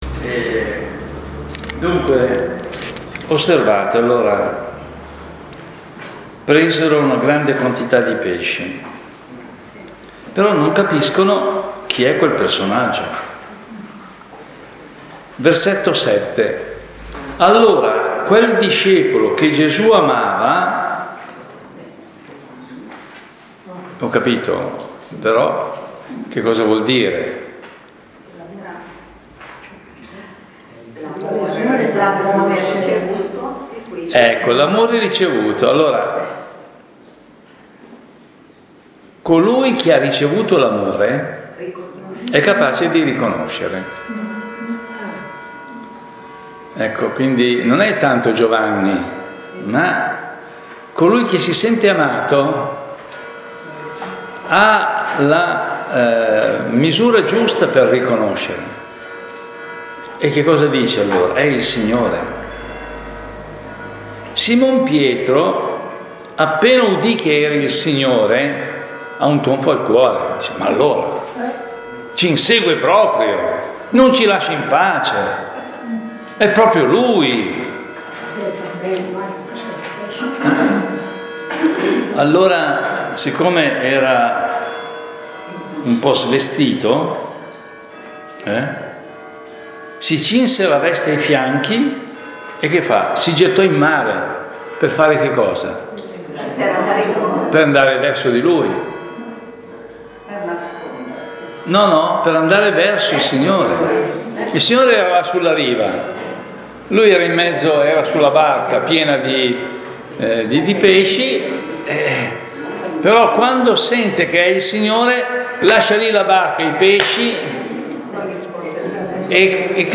Seconda parte della meditazione